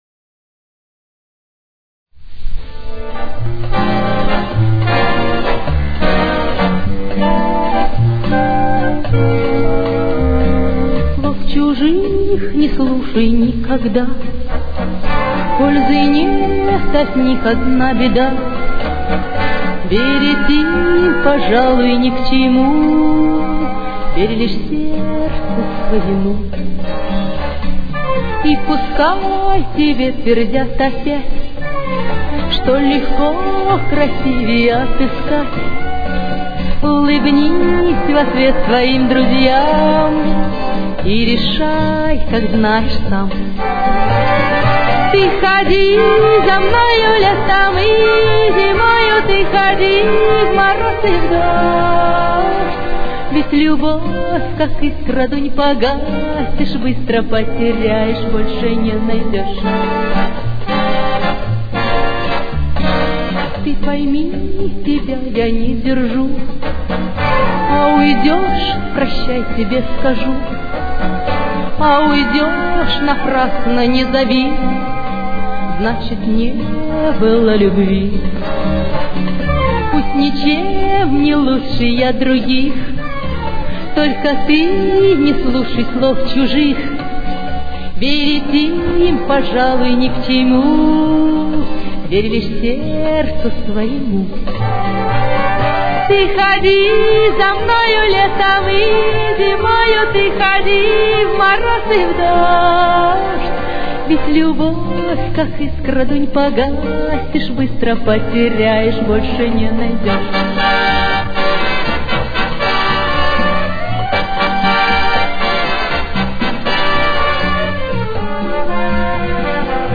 с очень низким качеством (16 – 32 кБит/с)
Темп: 119.